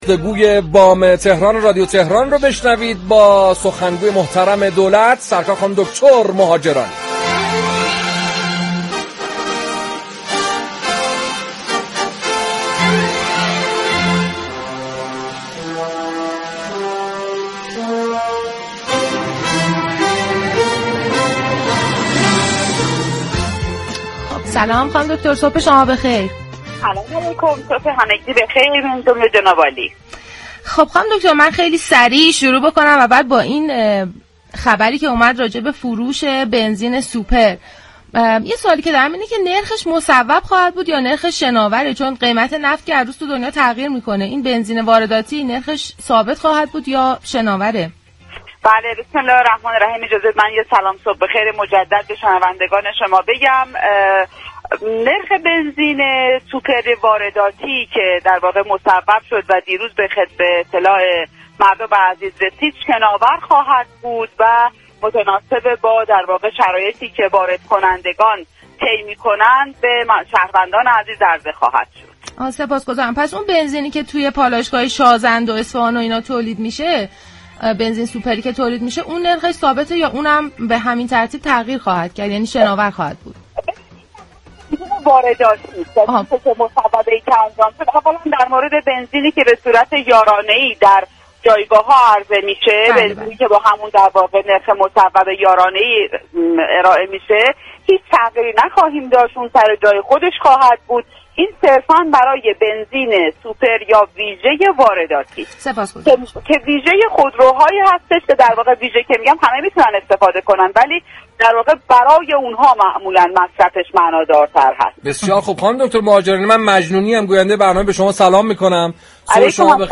نرخ بنزین سوپر وارداتی شناور خواهد بود به گزارش پایگاه اطلاع رسانی رادیو تهران، فاطمه مهاجرانی سخنگوی دولت در گفت و گو با «بام تهران» درخصوص بنزین سوپر وارداتی اظهار داشت: بنزین یارانه ای هیچ تغییر قیمتی نخواهیم داشت؛ ولی نرخ بنزین سوپر وارداتی، شناور خواهد بود.